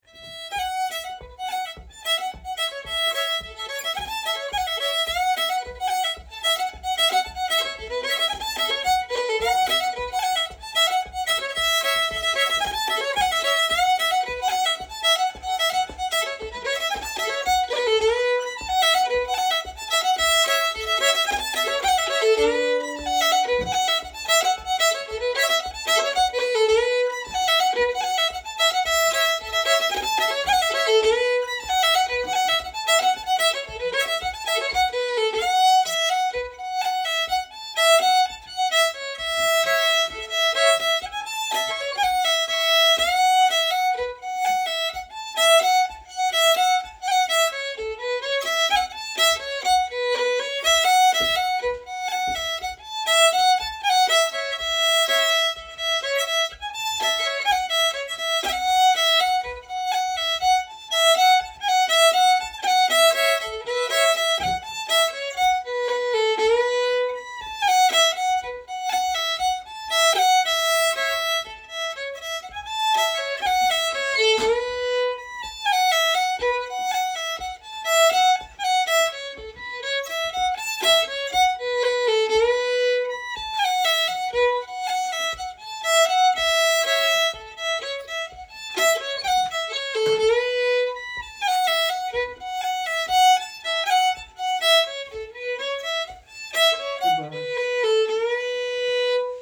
Sessions are open to all instruments and levels, but generally focus on the melody. Recordings of all the tunes we learn are archived here for future reference.
Composer Alasdair Fraser Type Reel Key Bm Recordings